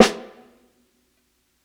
60s_SNARE_SOFT.wav